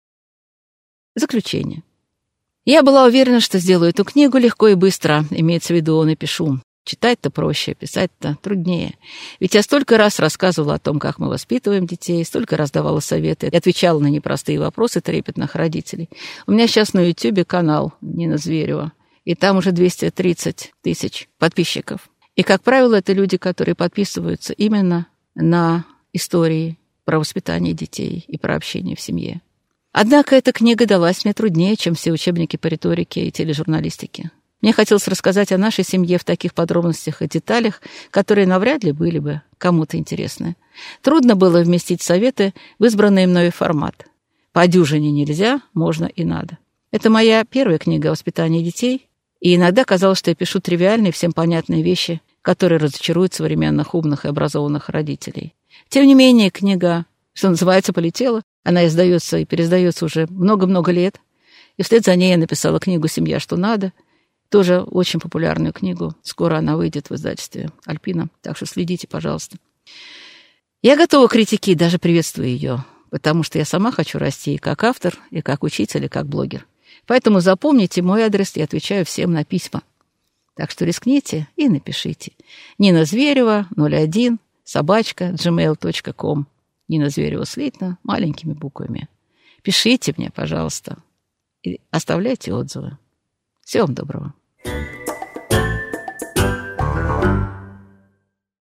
Аудиокнига Правила общения с детьми: 12 «нельзя», 12 «можно», 12 «надо» | Библиотека аудиокниг